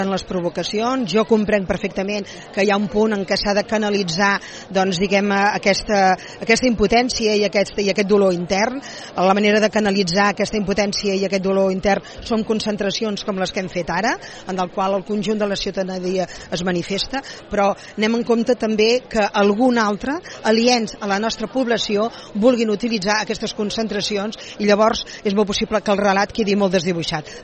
En una entrevista a l’Info Migdia, l’alcaldessa Montserrat Candini ha dit que agressions policials com les registrades anit, amb un balanç de 4 ferits i una quinzena de persones ateses, no es toleraran mai més aquí.